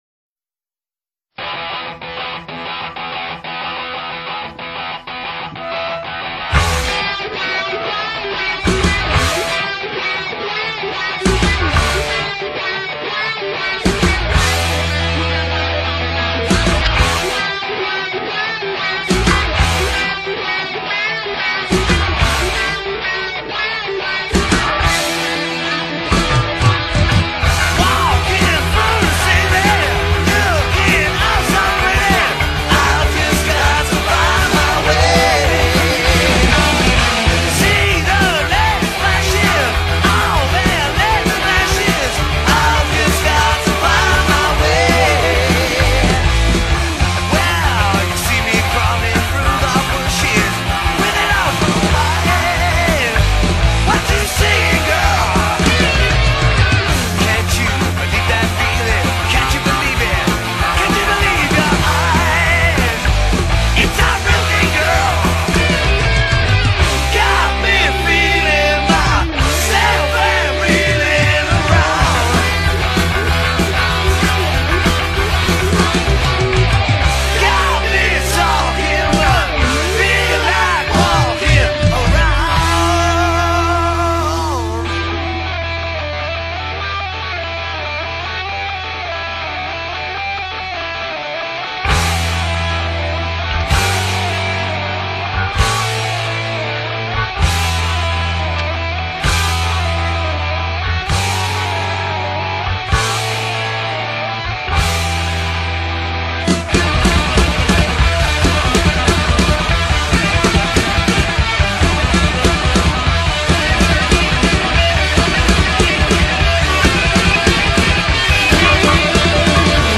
British heavy metal band